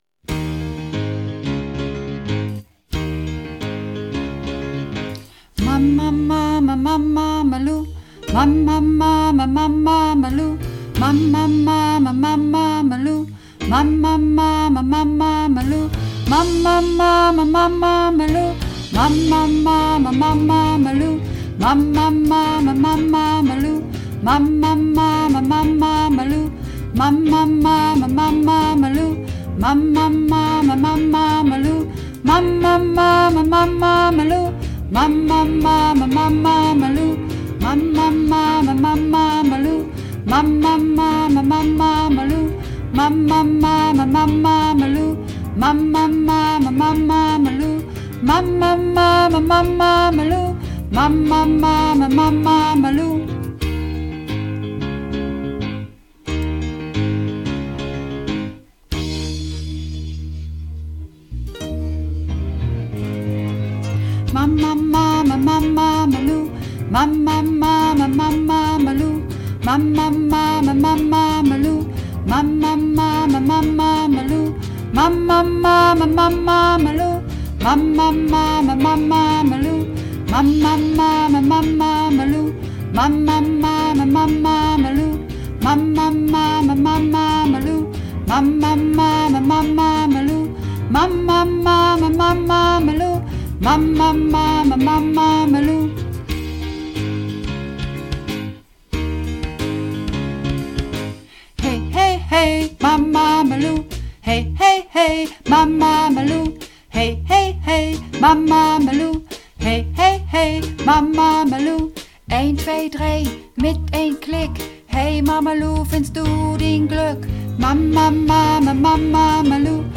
Übungsaufnahmen - Mama Loo
Mama Loo (Alt)
Mama_Loo__1_Alt.mp3